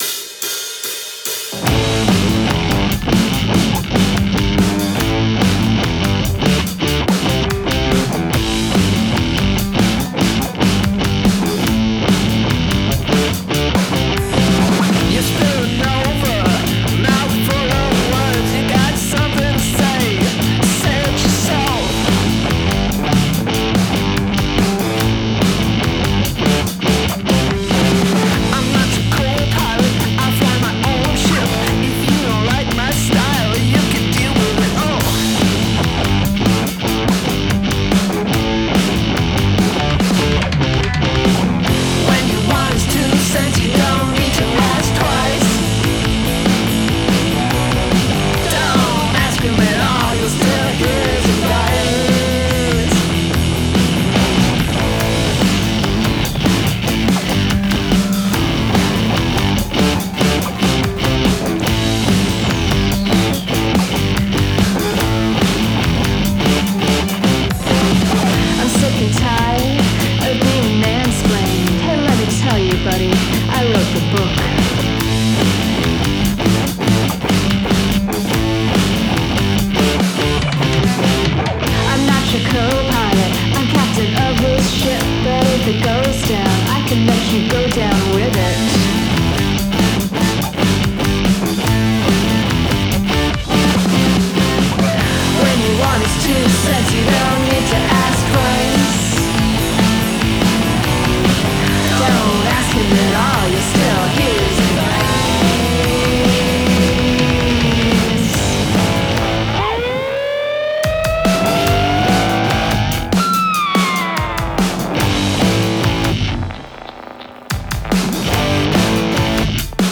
Guest Lead Vocals
The guitar tone is bad ass!
The riff is heavy and carries the song.